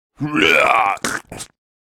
owl-puke.ogg